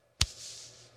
Вытер пот со лба